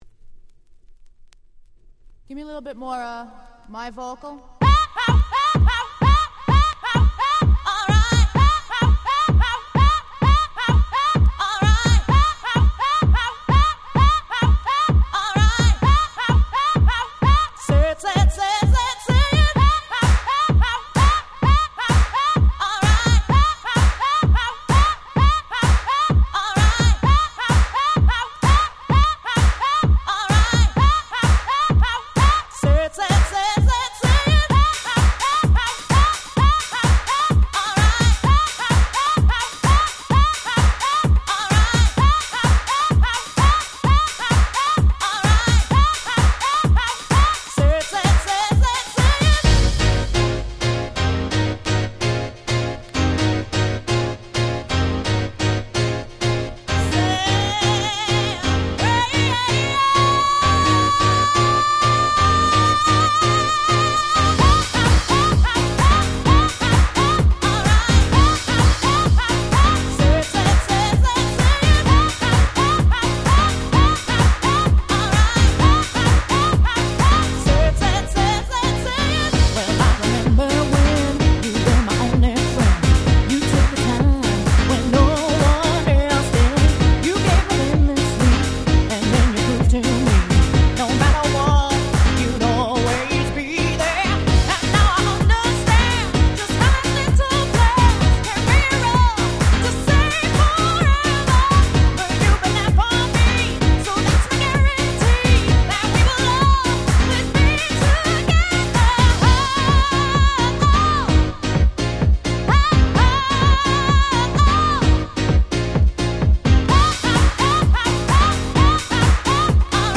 95' Smash Hit Vocal House / オネハ！！
ボーカルハウス